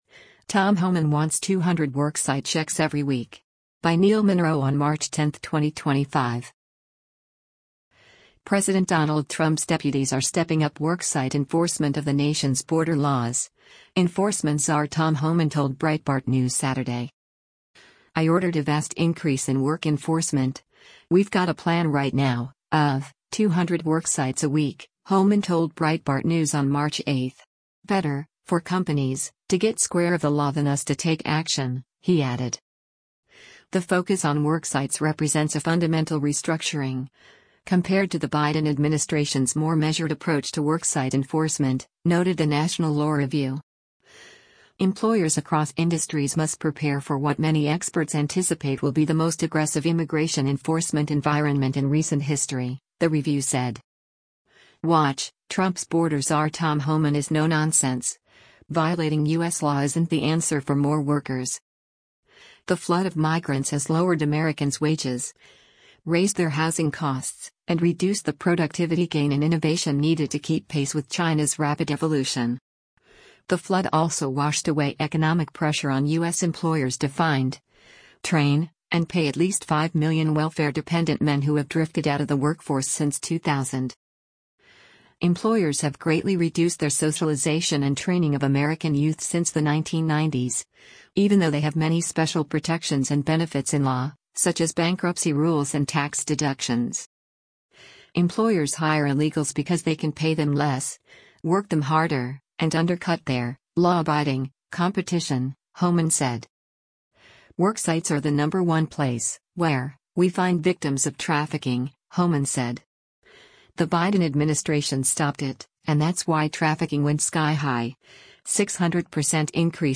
Border czar Tom Homan speaks to the media while visiting the US-Mexico border with Defense